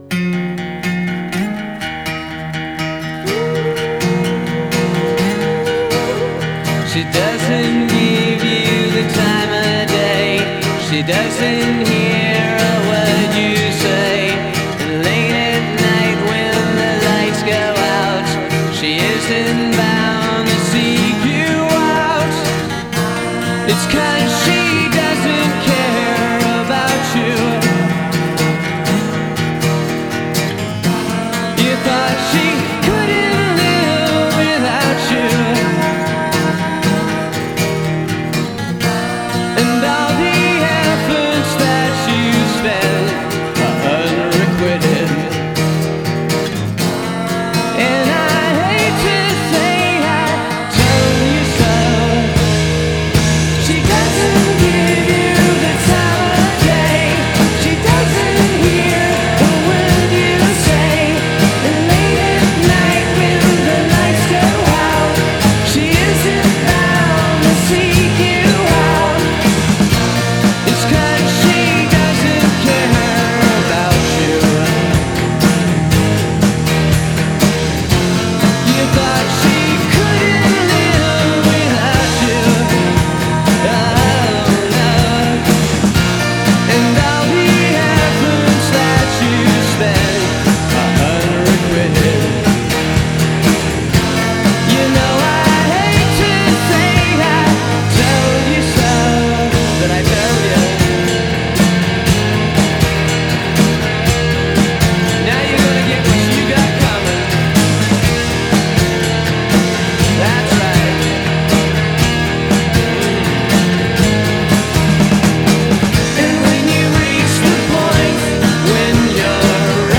early-Beatles-sounding